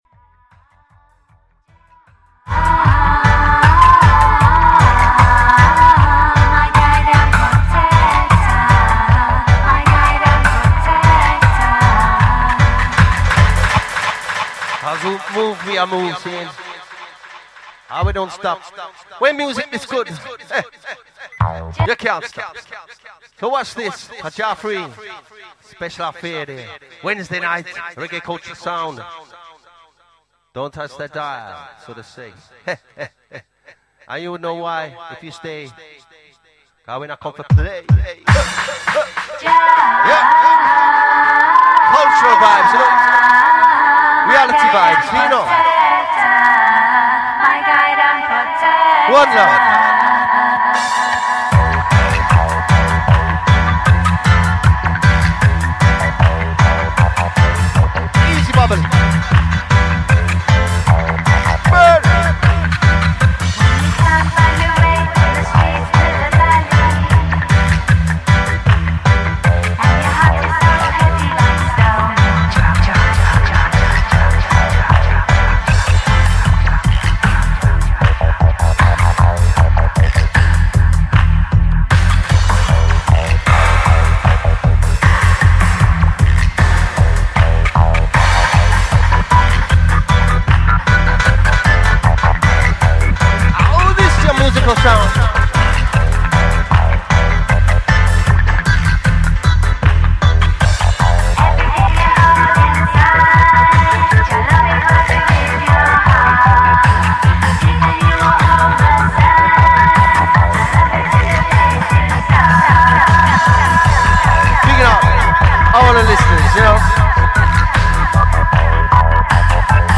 playin fe 2 hours 'live' set